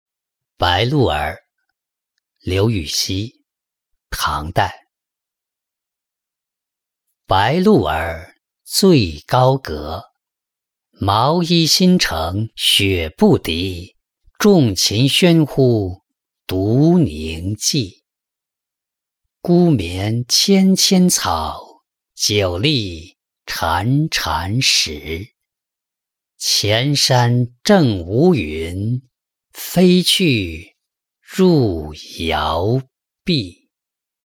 白鹭儿-音频朗读